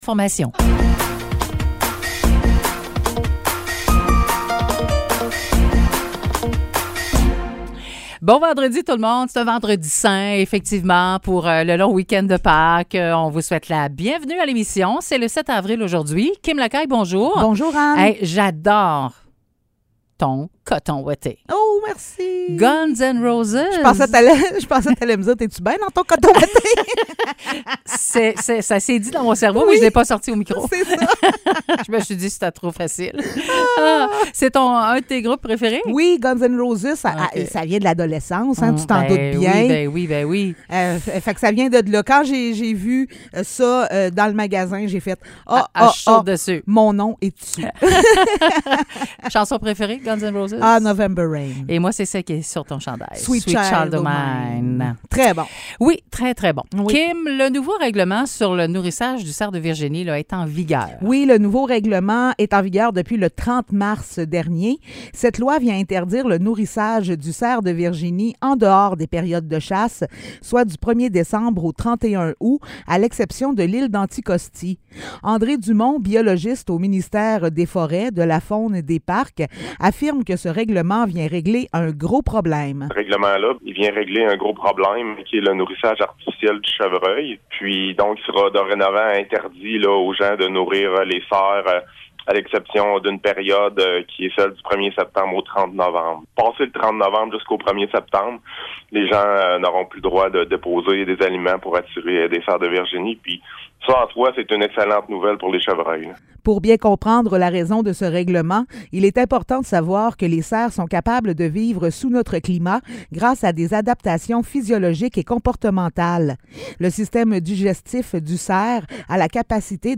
Nouvelles locales - 7 avril 2023 - 9 h